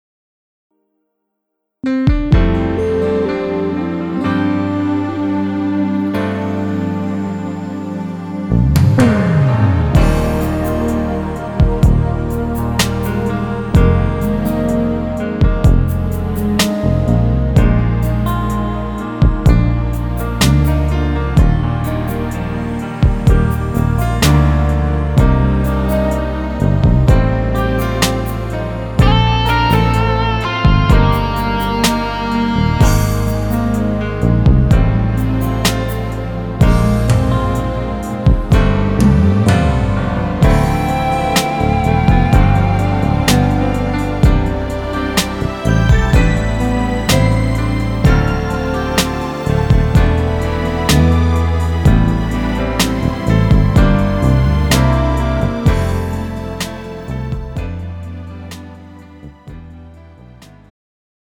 장르 축가 구분 Pro MR